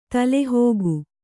♪ tale hōgu